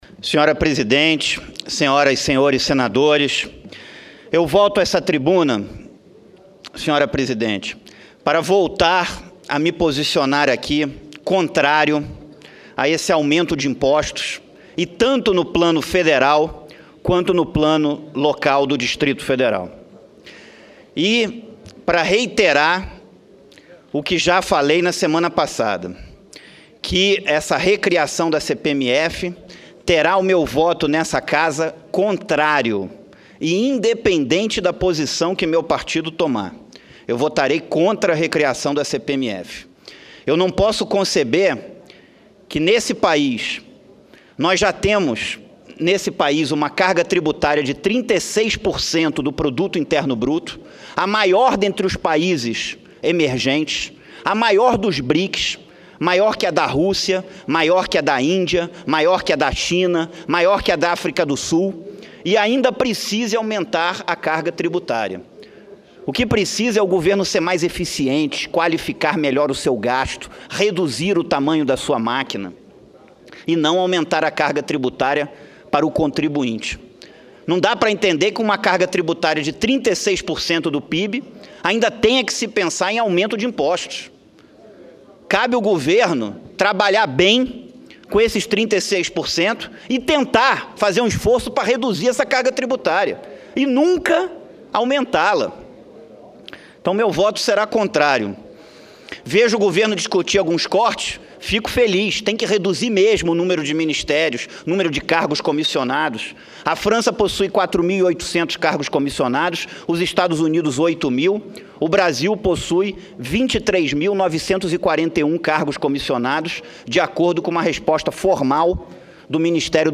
Discursos Reguffe comunica que é contra a recriação da CPMF RadioAgência Senado 16/09/2015